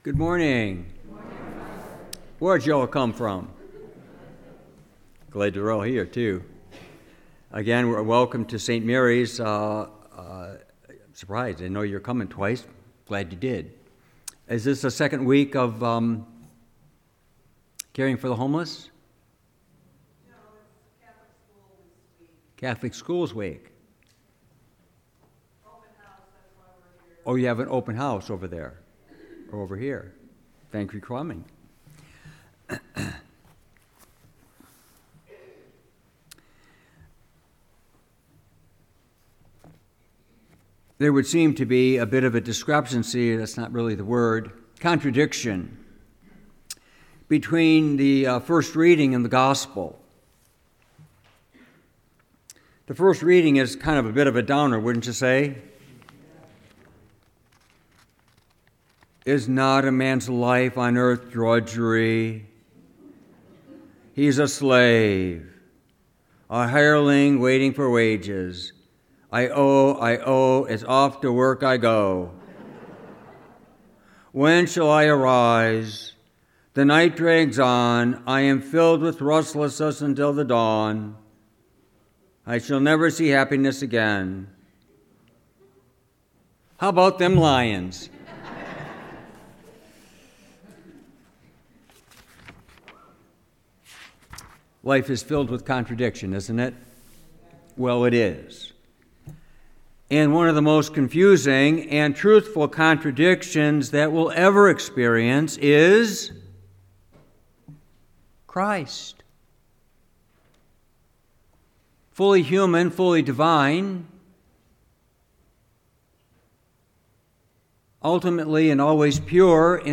Homily, February 4, 2024